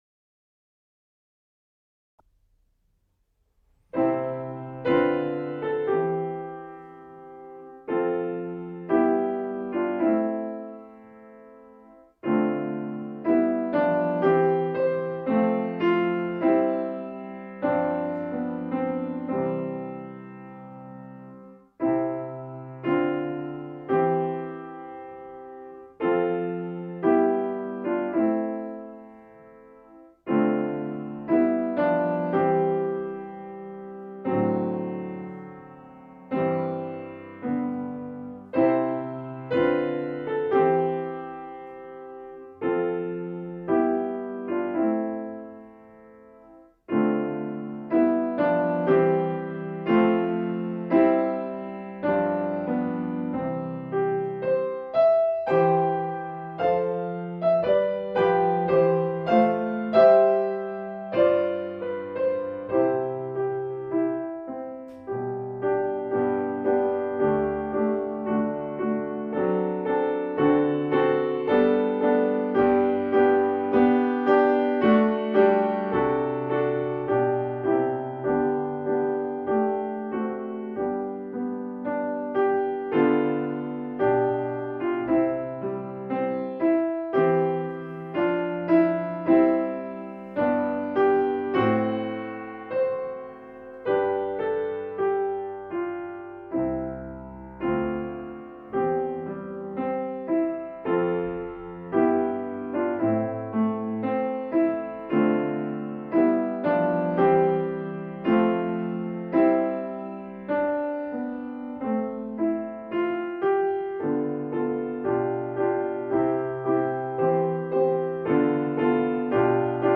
Hier findet ihr Noten, Begleitstimmen in mittlerer und tiefer Lage, ein Demo und eine Einführung von mir mit Tipps, wie ihr euch diese Arie erarbeiten könnt.